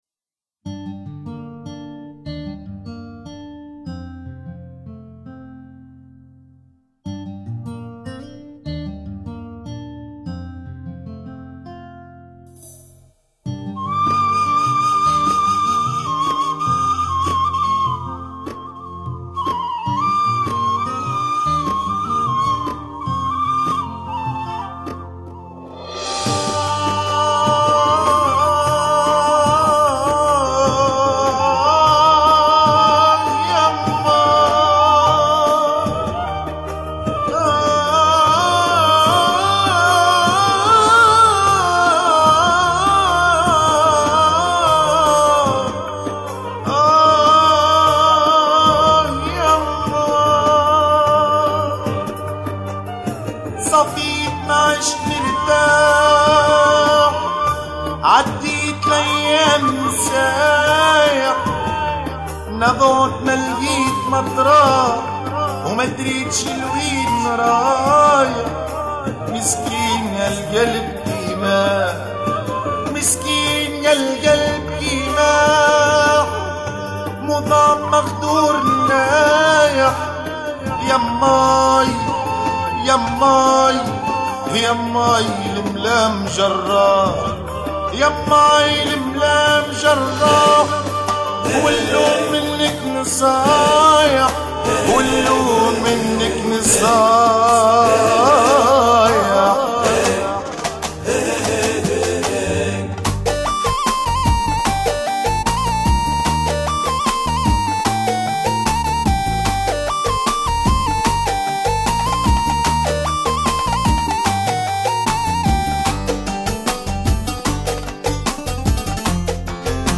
Bienvenue au site des amateurs de Mezoued Tunisien
la chanson